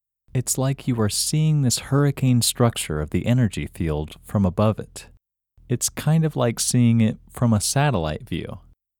IN – Second Way – English Male 9